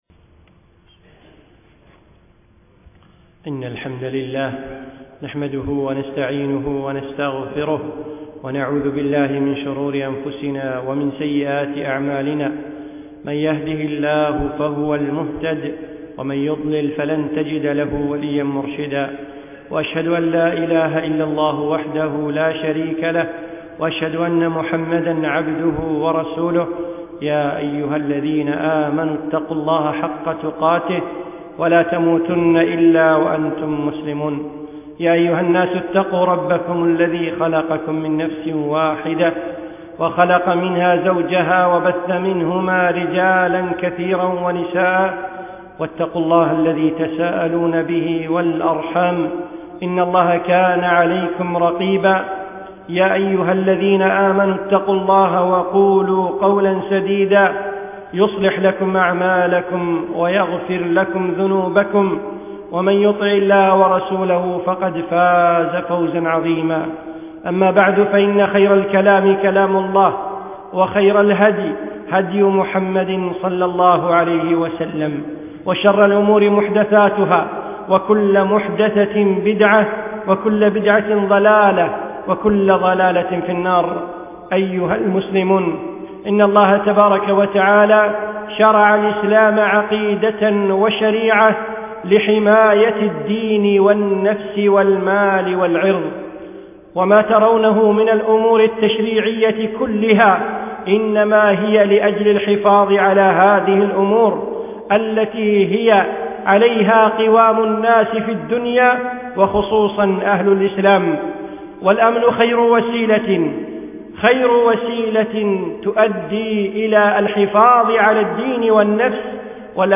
من خطب الشيخ في دولة الإمارات